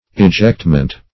Ejectment \E*ject"ment\, n.